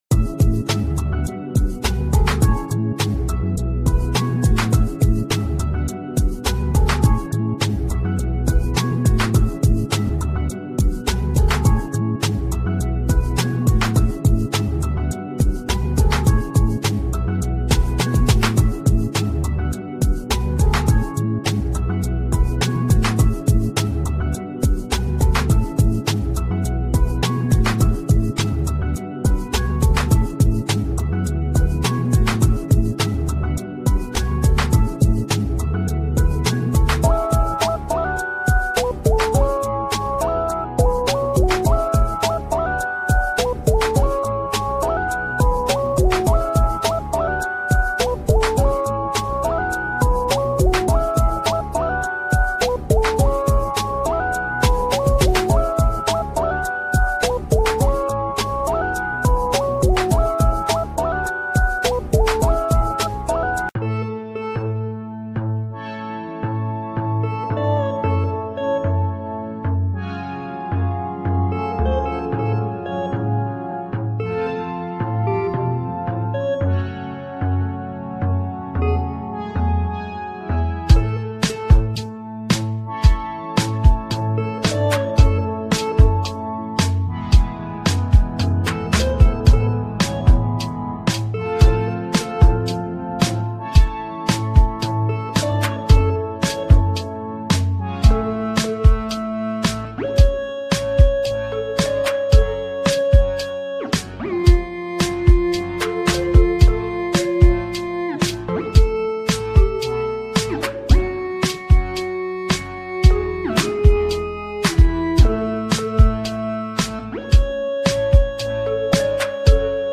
Peaceful Relaxing Music